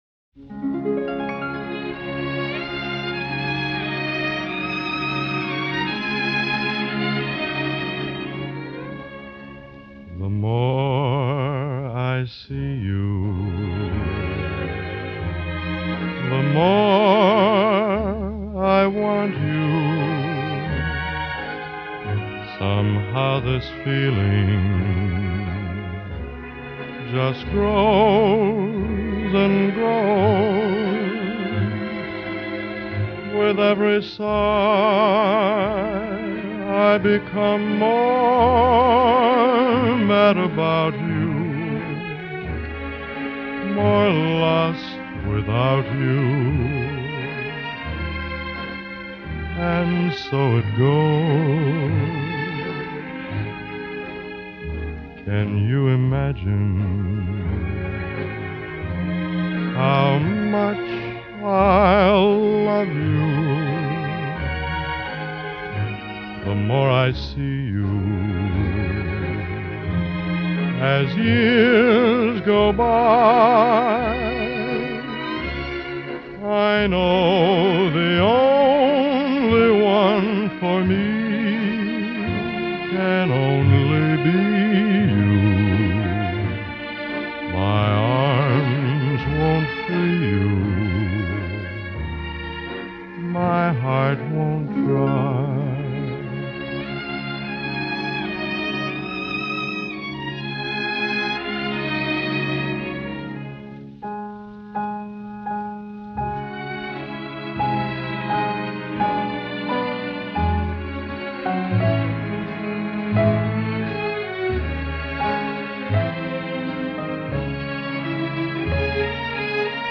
Обладатель баритона красивого глубокого тембра.